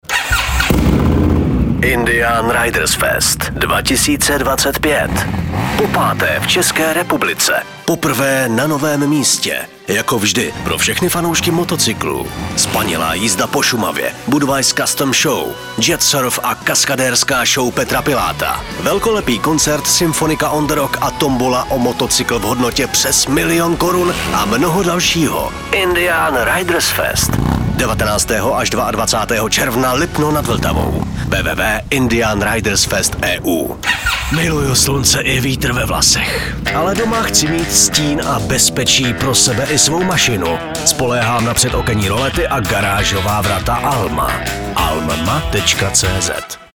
Rádiový spot – promo + 10s